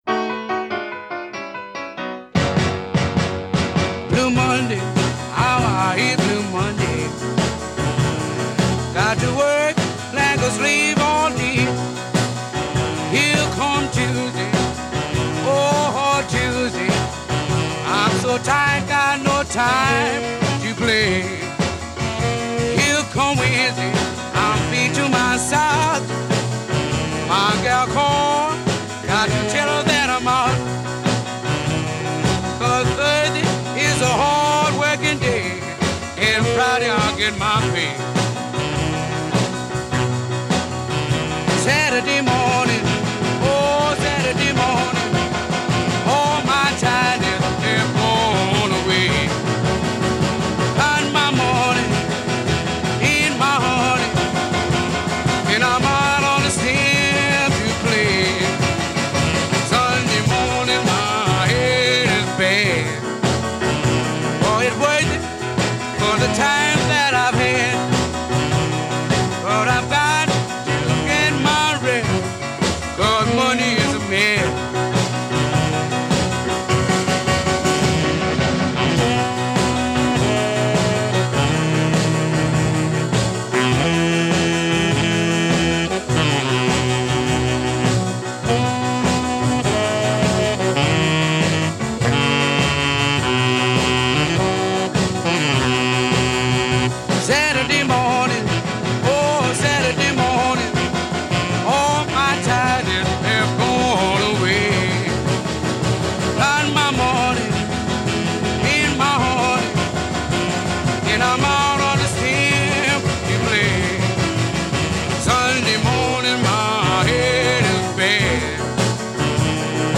Американский пианист
хитам буги-вуги